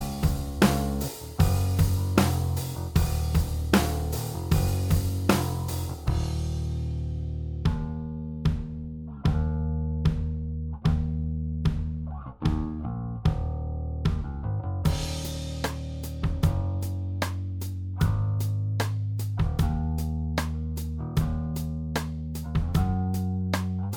Minus All Guitars Rock 4:03 Buy £1.50